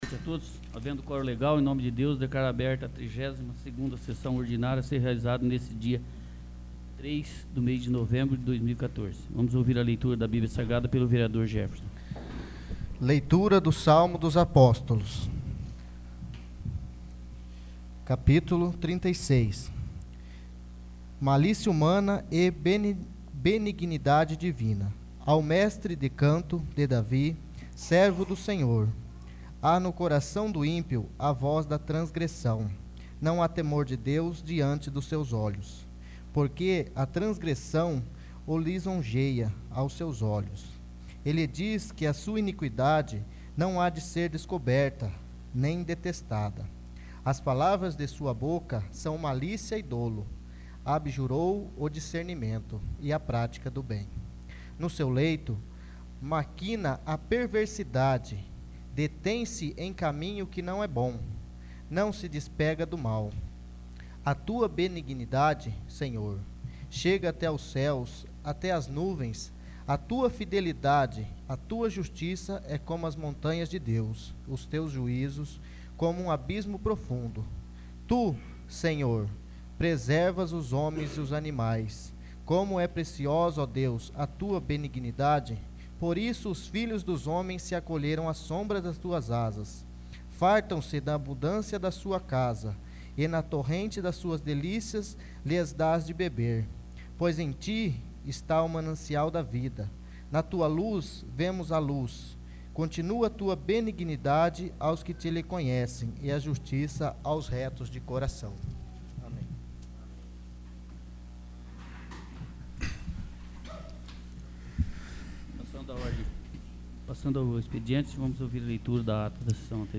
32º. Sessão Ordinária